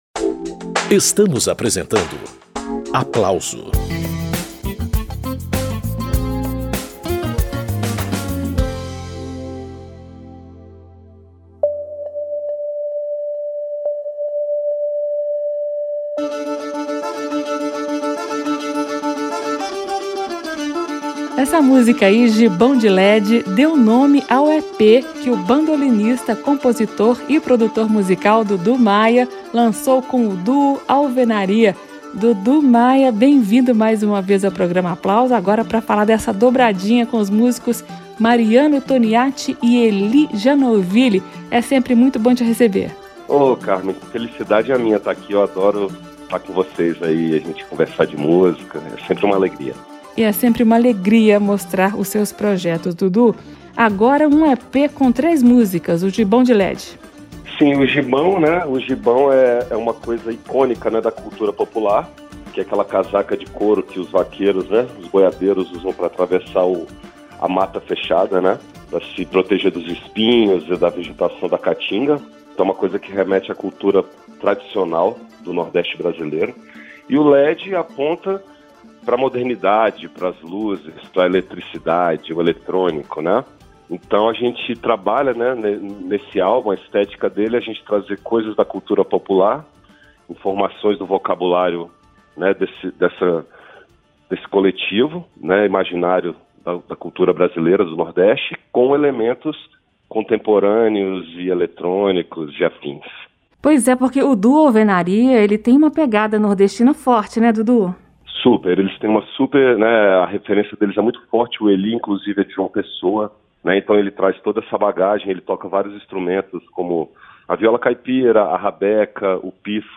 Nas 3 faixas do EP, a trinca de instrumentistas cria sons que fazem comunhão entre a música tradicional nordestina e sonoridades eletroacústicas.